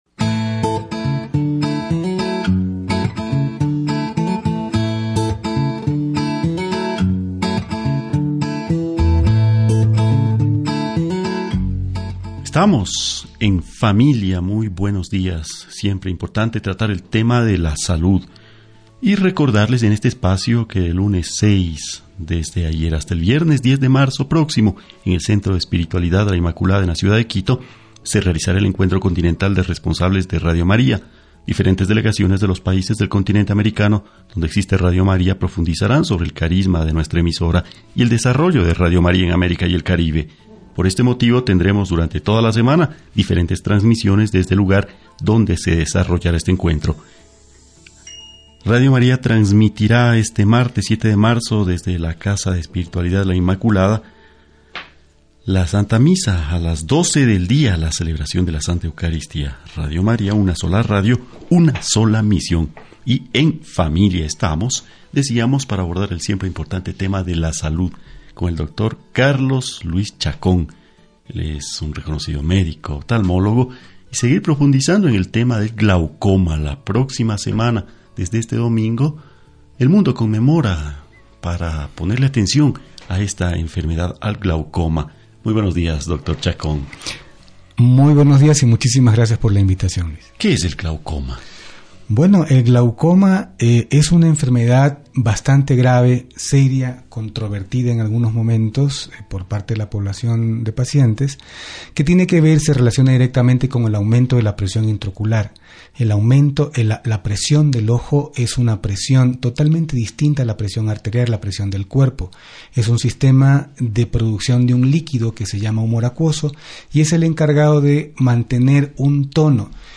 World Glaucoma Week » ENTREVISTA RADIAL